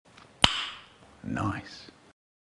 click nice 3 sound effects
click-nice-3